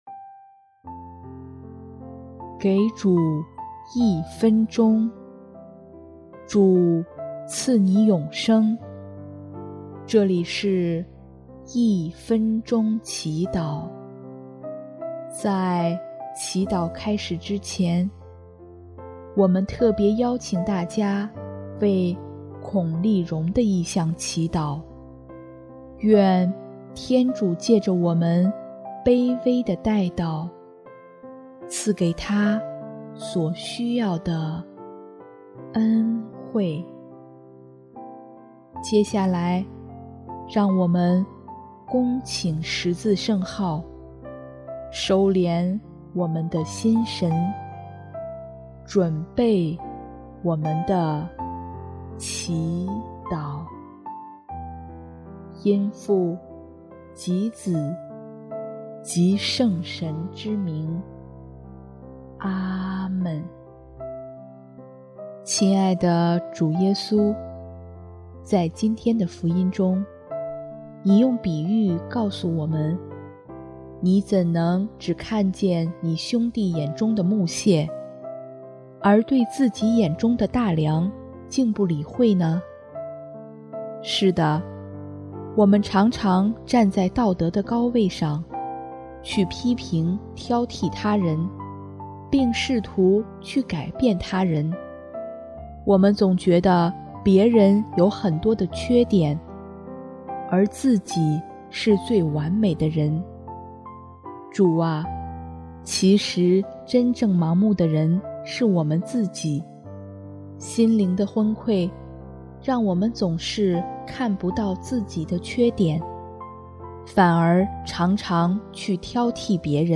音乐： 主日赞歌《厄法达》